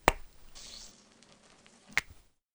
morphine_01.ogg